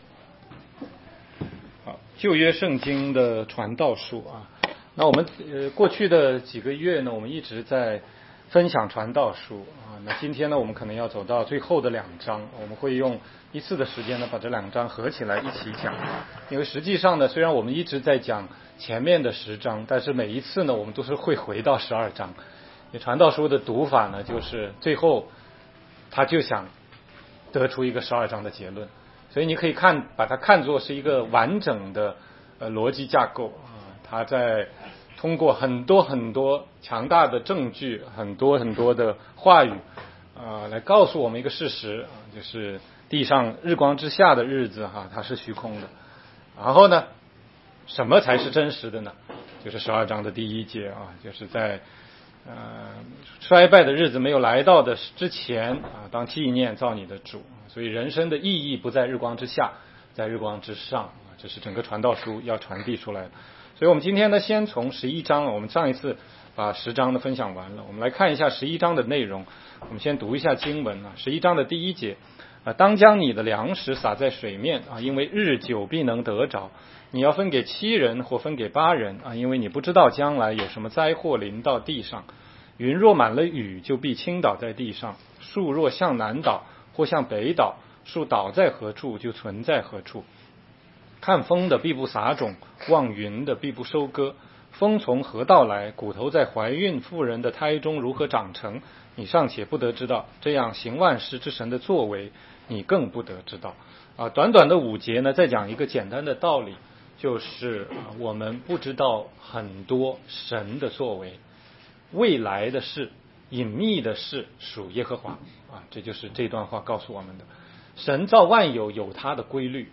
16街讲道录音 - 从传道书看人生的意义：从虚空到记念造你的主（12）
全中文查经